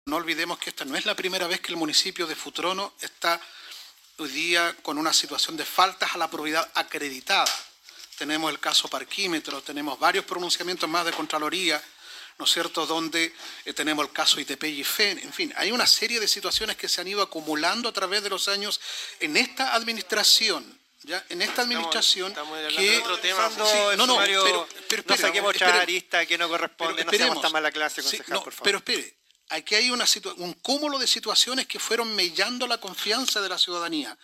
Sesión Concejo Municipal de Futrono | Carputa transmisión municipio